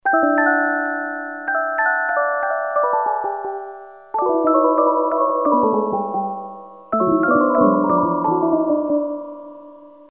hellraiser carillon
Synthesis: FM